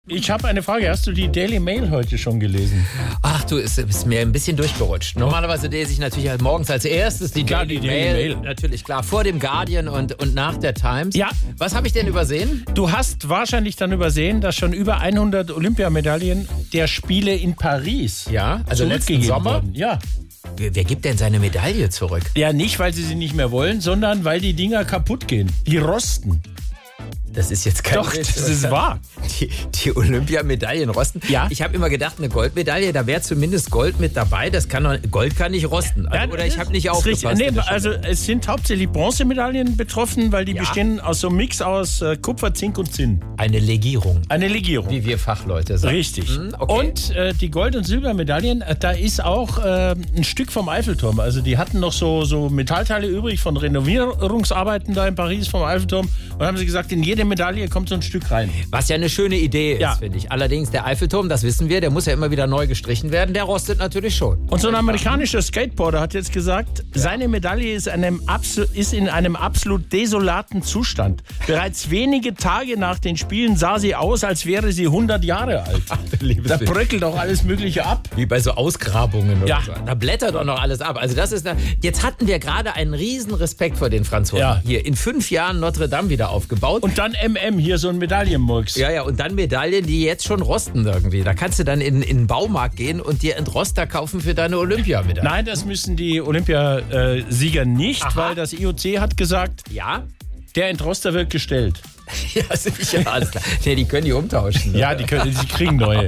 Nachrichten Olympia: Über 100 Medaillen zurückgegeben